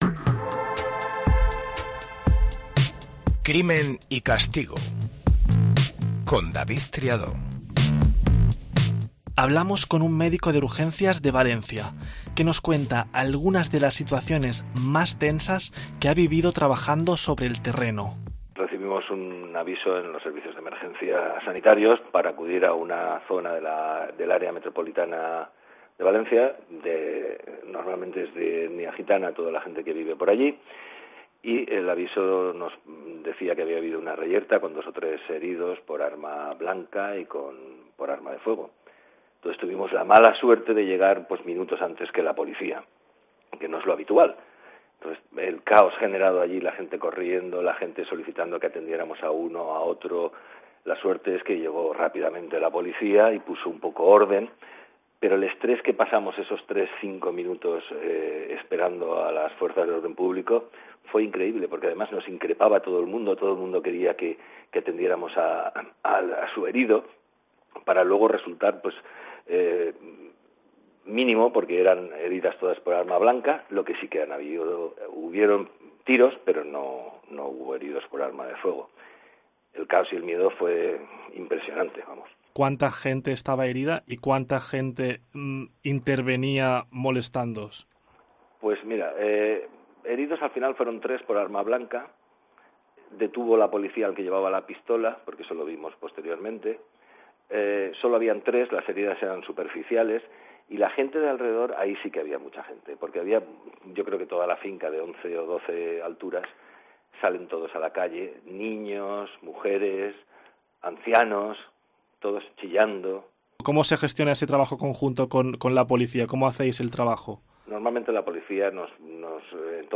Un médico de Urgencias nos cuenta uno de sus servicios más difíciles: “Nos increpaba todo el mundo”